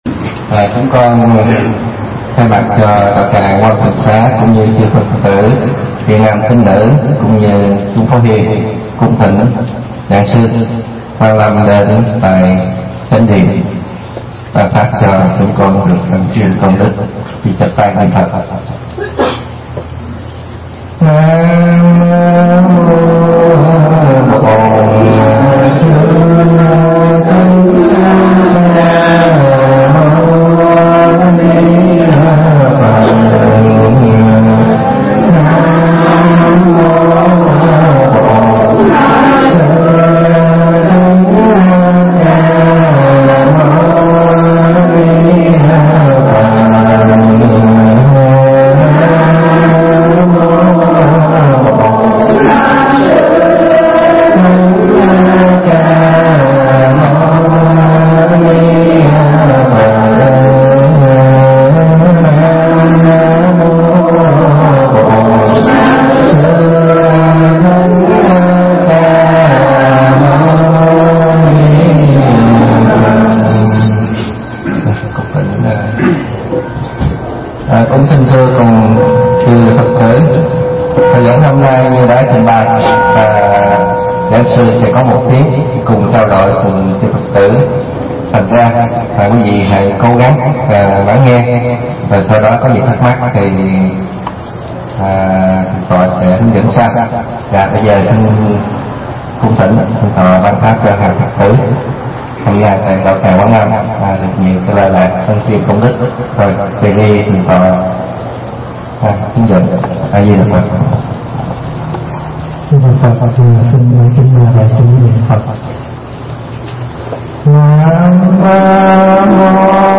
Tải mp3 Thuyết Giảng Nhân và Trí – Đại Đức Thích Pháp Hòa thuyết giảng tại Tịnh xá Quan lâm, Hoa Kỳ